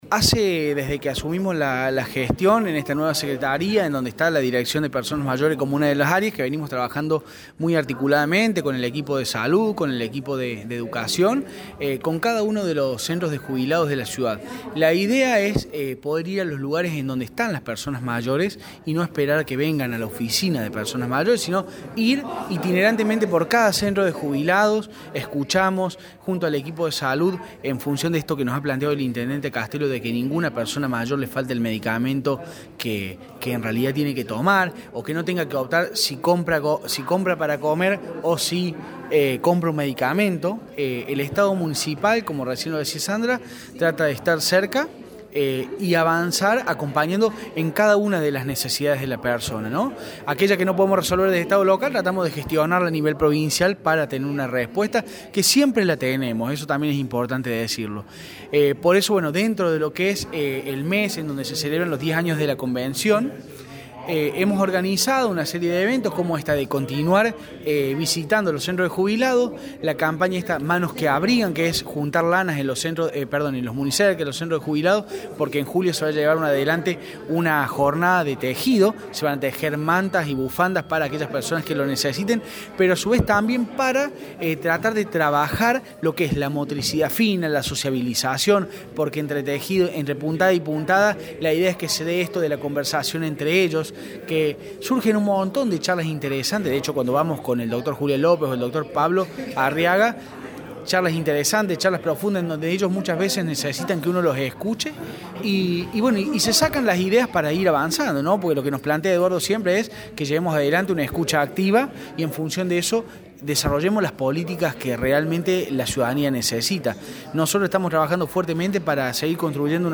El secretario de Desarrollo Humano y Territorio, Agustín Turletti, dialogó con Radio Show.